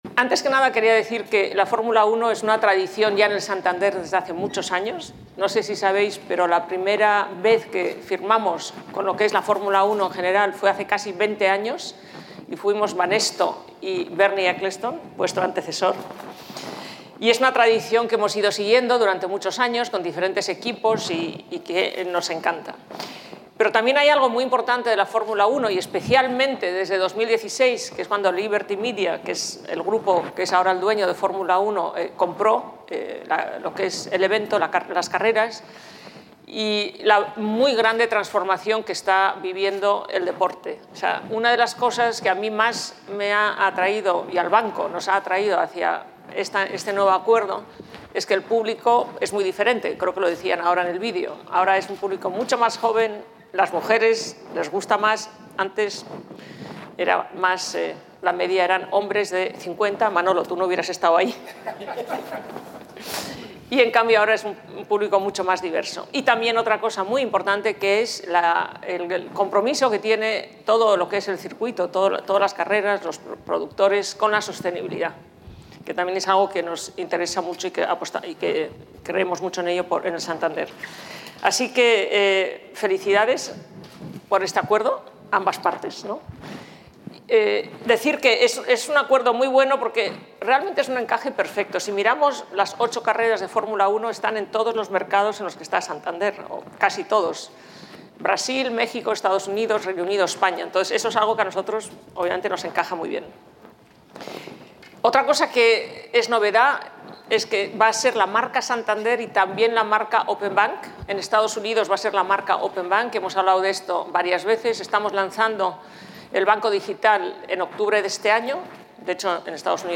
rueda-de-prensa-completa-santander-fi.mp3